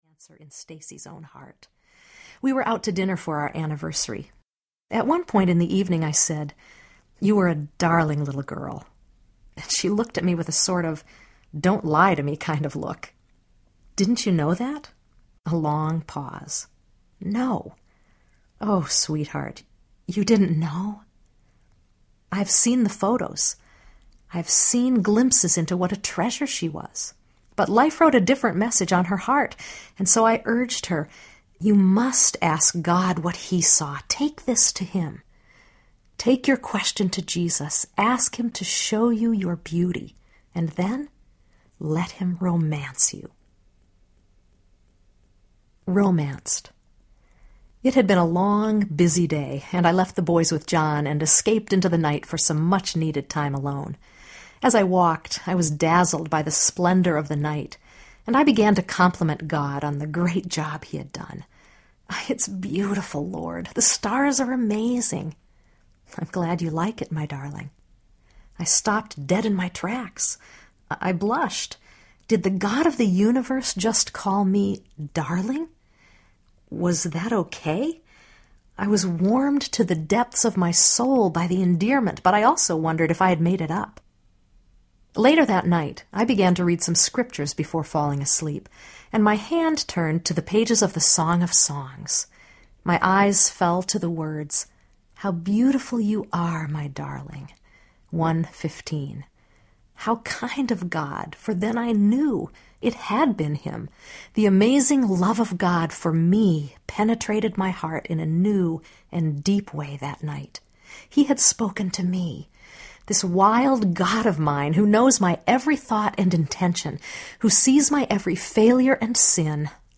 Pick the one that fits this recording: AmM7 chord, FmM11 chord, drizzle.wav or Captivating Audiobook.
Captivating Audiobook